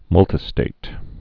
(mŭltĭ-stāt)